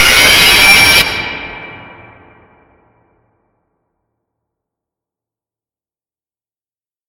Jolly: Robotic Life 2.0 Jumpscare Sound 5 - Bouton d'effet sonore